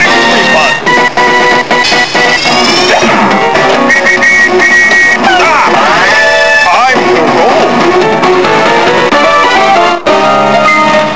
Download 121Kb Jubelmusikk :)